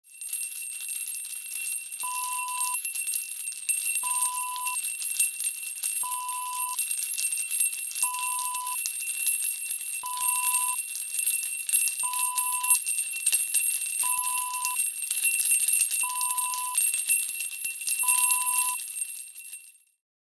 Small Bells Ringing Wav Sound Effect
Description: The sound of several small bells ringing
Properties: 48.000 kHz 24-bit Stereo
A beep sound is embedded in the audio preview file but it is not present in the high resolution downloadable wav file.
Keywords: small, tiny, little, bell, bells, brass, jingle, christmas, bell, ring, ringing
small-bells-ring-preview-1.mp3